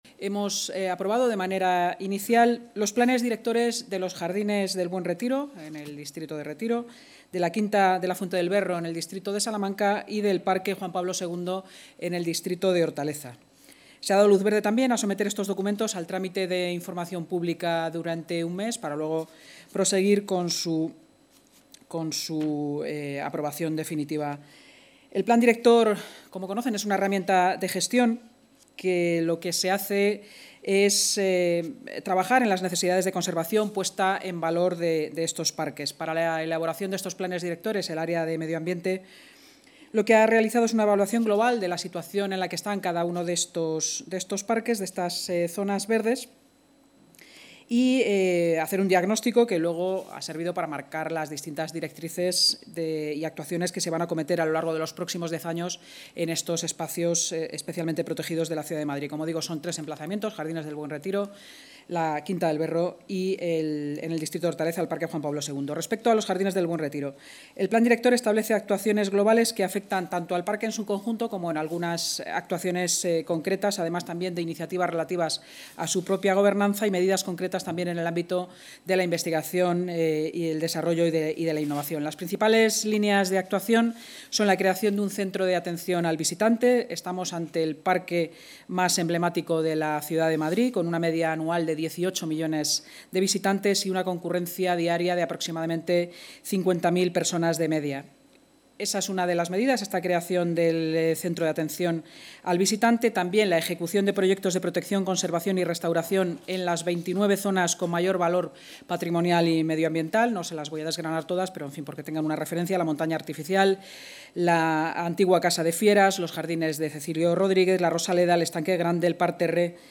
La Junta de Gobierno del Ayuntamiento de Madrid ha aprobado inicialmente este jueves los planes directores de los jardines del Buen Retiro (distrito de Retiro), de la Quinta de la Fuente del Berro (Salamanca) y del parque Juan Pablo II (Hortaleza). También ha dado luz verde a someter estos documentos al trámite de información pública durante un mes, según ha explicado la vicealcaldesa y portavoz municipal, Inma Sanz.